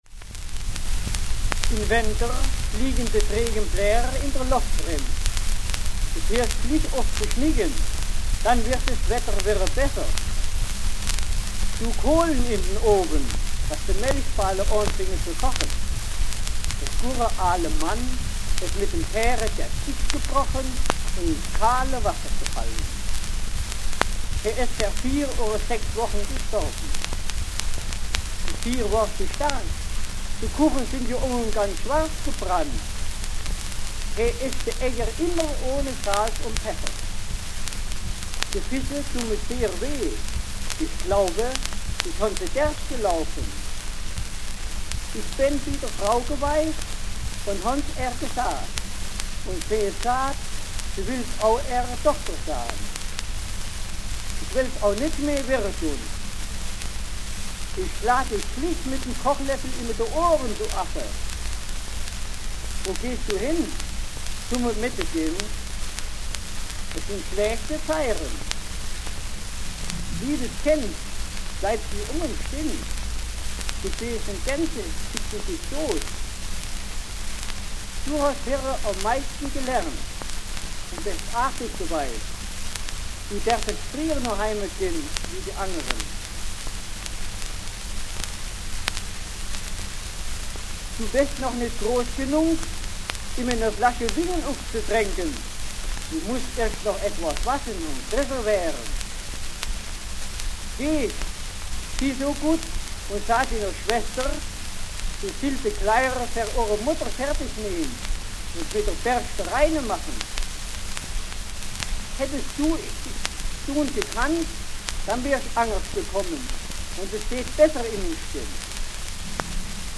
Tonaufnahme mit 37 Wenkersätzen in einer hessischen Mundart
Hessisch (Deutschland), Wenkersche Sätze - LA 122 Schellackplatte von 1923
Das Lautarchiv der Humboldt-Universität besitzt 564 Tonaufnahmen auf Schellackplatten mit gesprochenen Wenkersätzen.
Der Sprecher stammt aus dem hessischen Bad Wildungen, beginnt mit dem ersten der Sätze „Im Winter fliegen die trockenen Blätter in der Luft herum“ und endet mit dem 37. der 40 Wenkersätze „Die Bauern hatten fünf Ochsen und neun Kühe und zwölf Schäfchen vor das Dorf gebracht, die wollten sie verkaufen.“